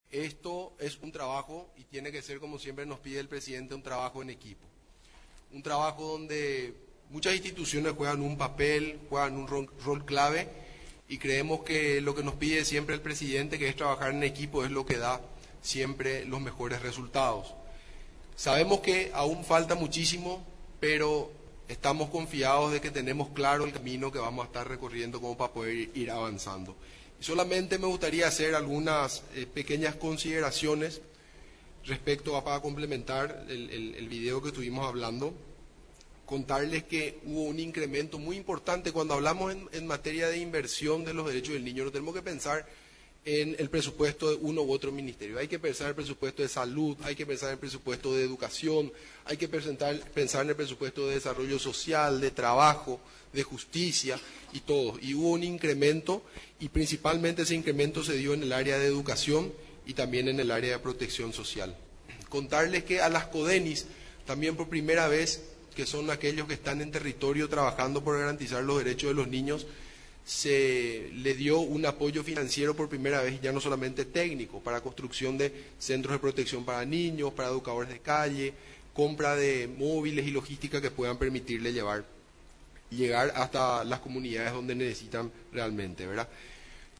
En el informe de Gestión, realizado en la Residencia Presidencial de Mburivichá Róga, estuvo presente en representación del Ejecutivo, la Primera Dama de la Nación, Leticia Ocampos, además de otras autoridades nacionales.